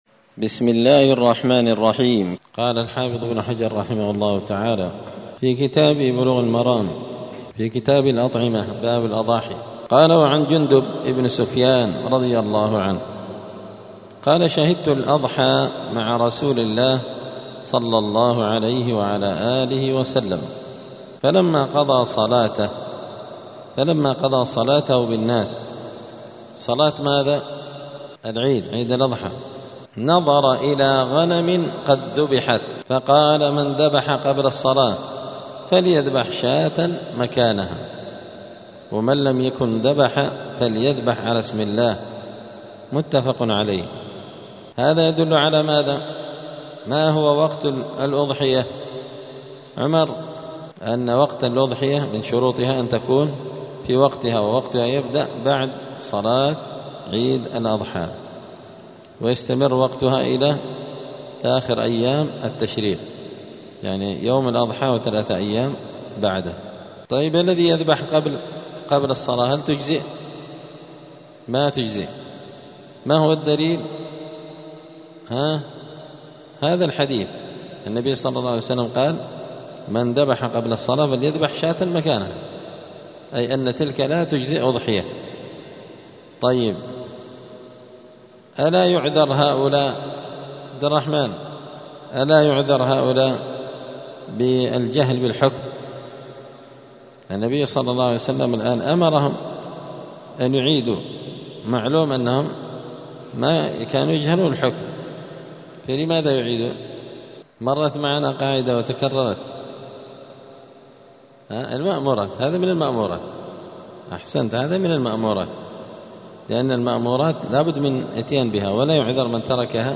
*الدرس الثالث والعشرون (23) {وقت الأضحية}*
دار الحديث السلفية بمسجد الفرقان قشن المهرة اليمن